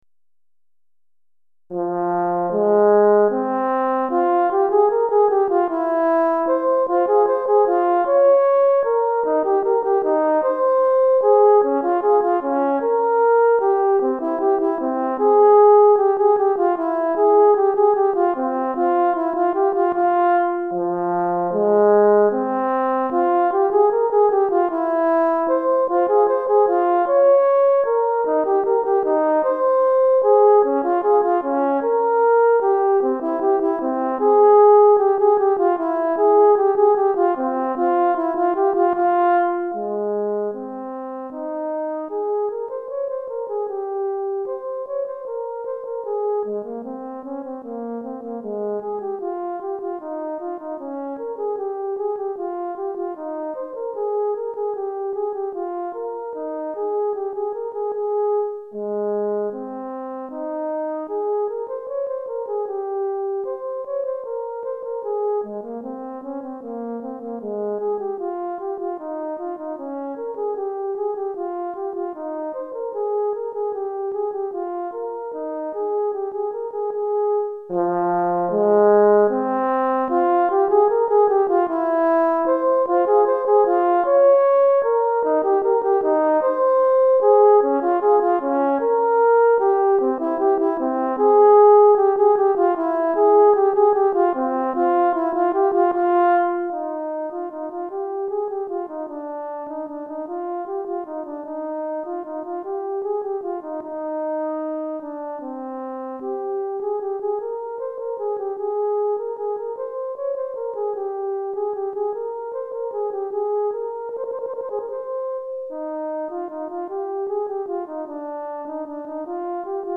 Cor en Fa Solo